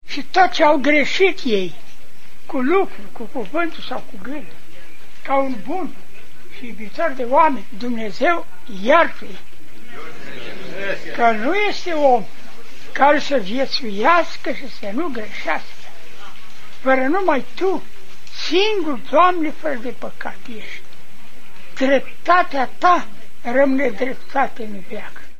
Enoriasii din Cosambesti au sarbatorit astazi hramul bisericutei din vatra satului, folosita in ultimii ani doar la slujbe de inmormantare.